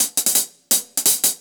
Index of /musicradar/ultimate-hihat-samples/170bpm
UHH_AcoustiHatC_170-03.wav